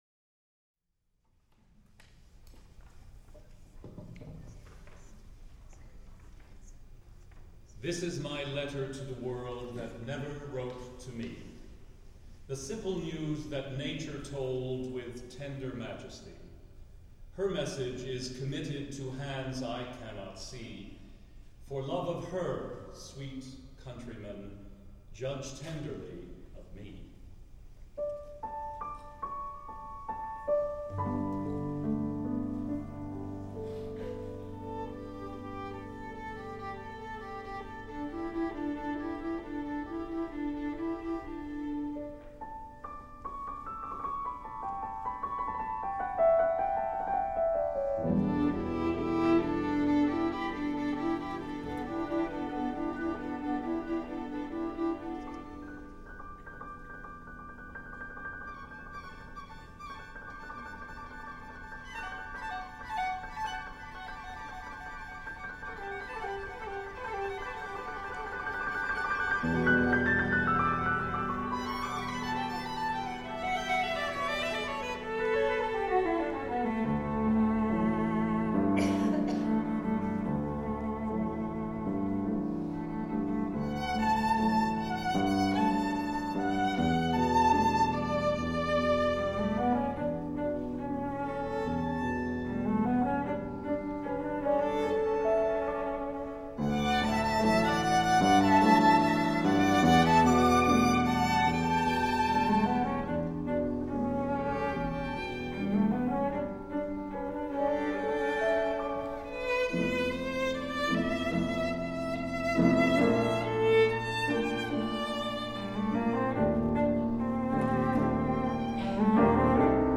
for Piano Quartet (2001)
piano quartet (violin, viola, cello, and piano)